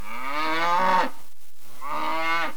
دانلود صدای حیوانات جنگلی 55 از ساعد نیوز با لینک مستقیم و کیفیت بالا
جلوه های صوتی